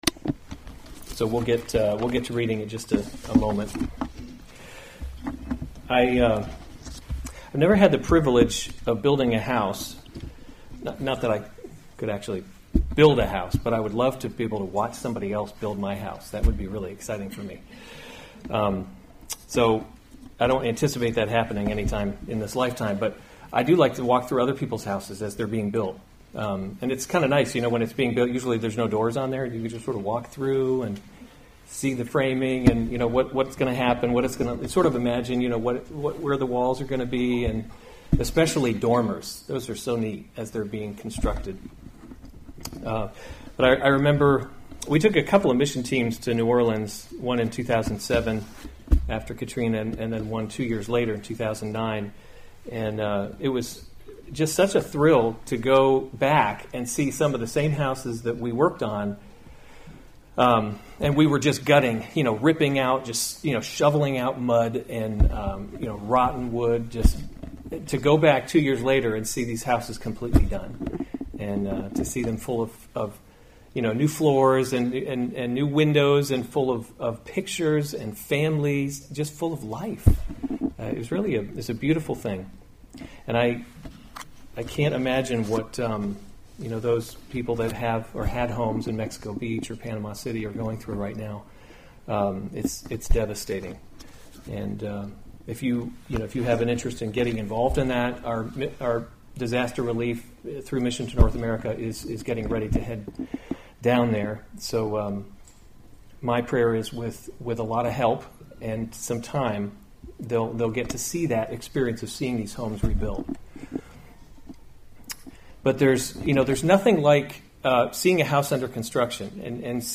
October 13, 2018 1 Kings – Leadership in a Broken World series Weekly Sunday Service Save/Download this sermon 1 Kings 6 Other sermons from 1 Kings Solomon Builds the Temple […]